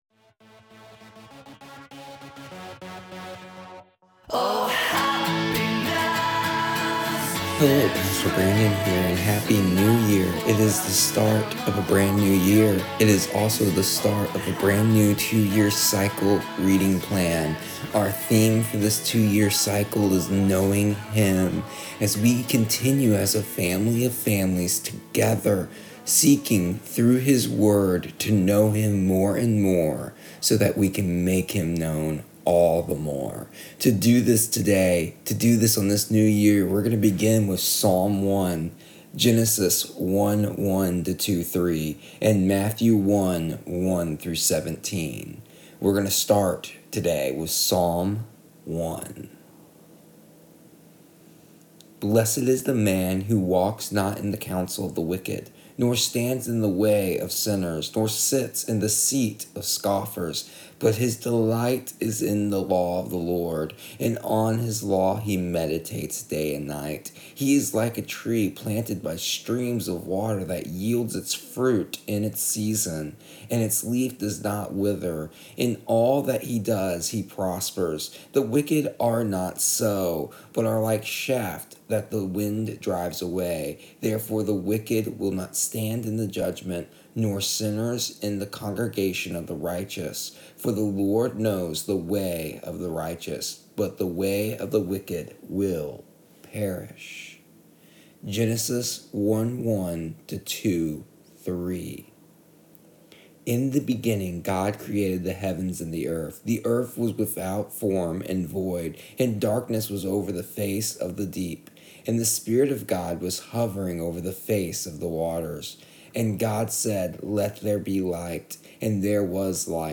Here is the audio version of our daily readings from our daily reading plan Knowing Him for January 1st, 2022.